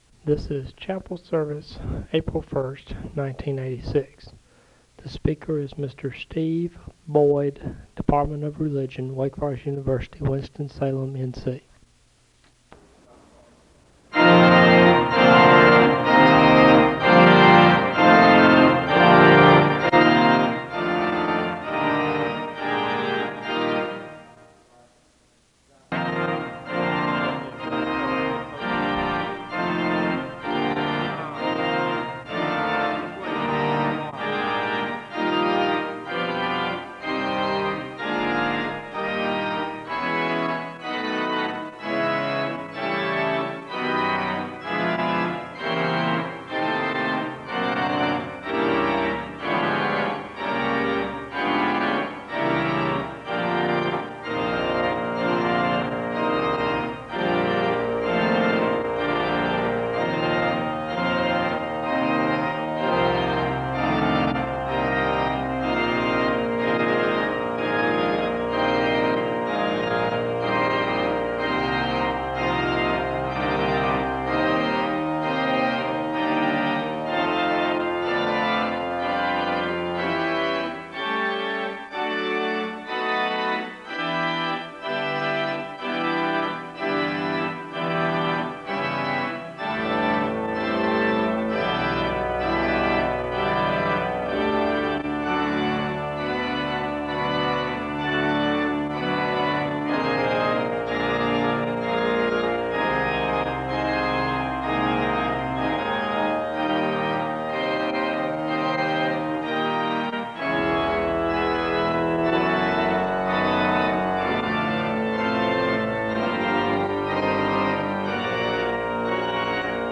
The service begins with organ music (0:00-6:55). Prayer concerns and celebrations are shared with the congregation and there is a moment of prayer (6:56-10:35).